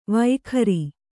♪ vaikhari